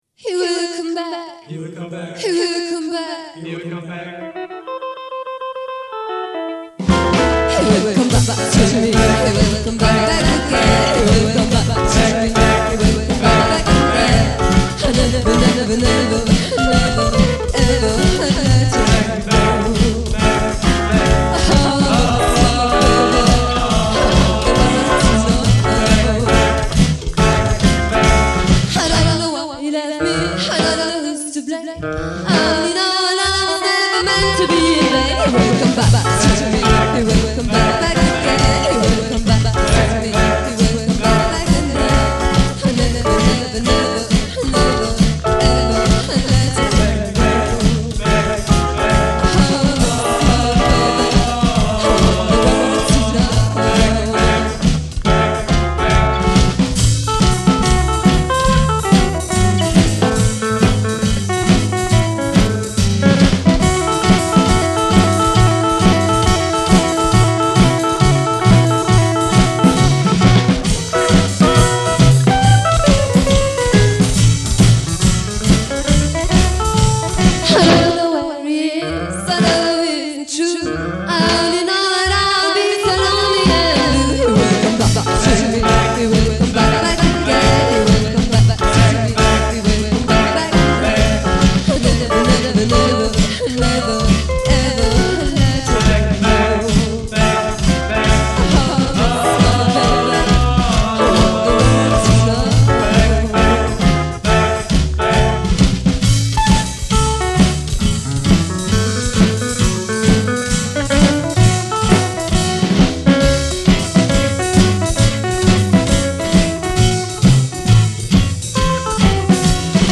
Frau singt in der Männerdomäne Rock ’n Roll.